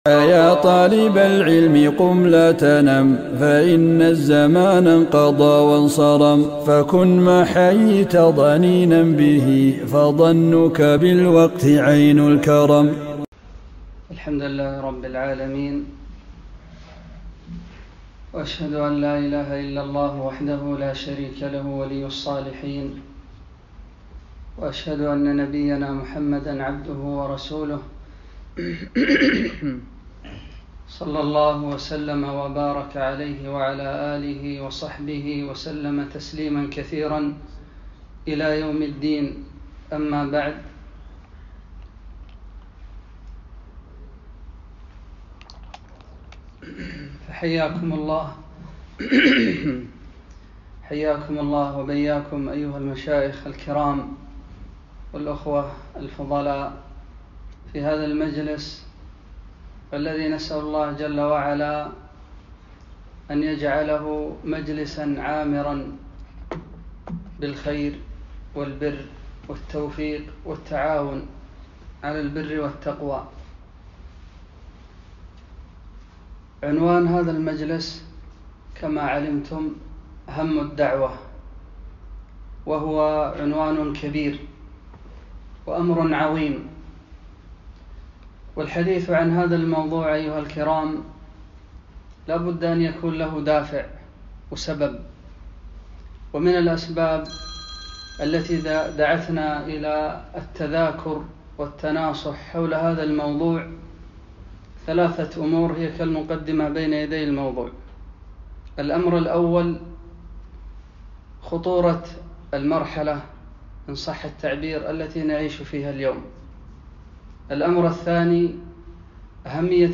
محاضرة - هَمُّ الدعوة